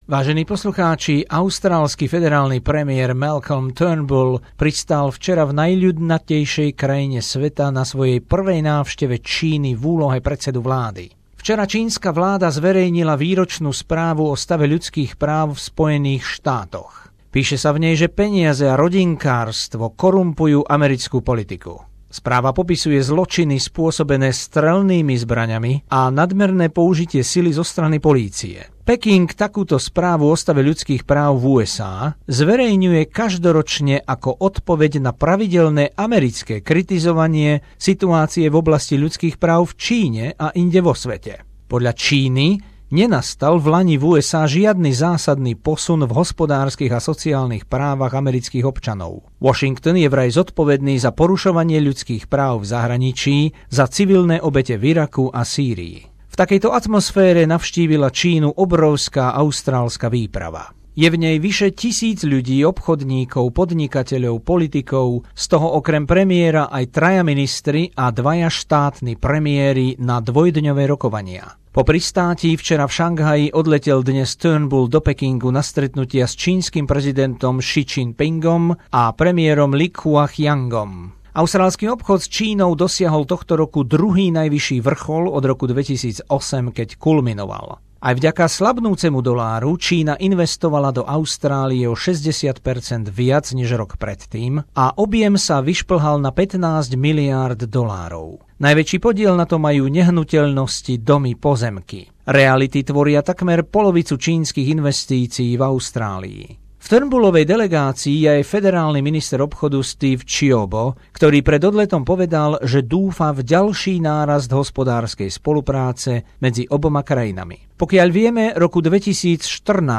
Rozšírená správa o austrálskej návšteve v Číne, vzťahoch a hospodárstve